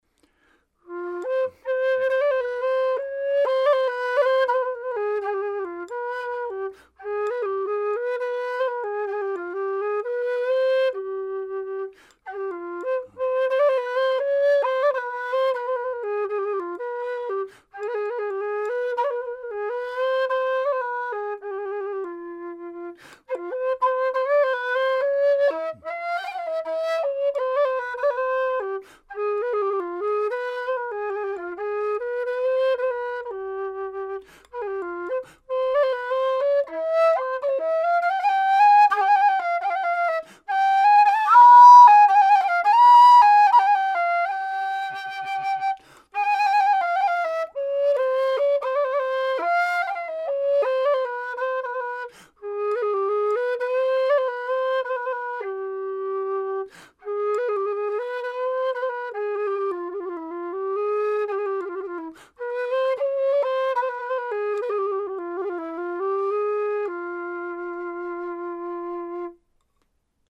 Some sound samples from various Gypsy whistles:
Low F-Gypsy sound sample: (:audioplayer
Note: although the scale patterns are given, as intervals between notes in semi-tones, the Gypsy whistles are not tuned in equal temperament, but for a good sounding intonation, especially for the harmonic and double harmonic scales.
LowFgypsy-impro.mp3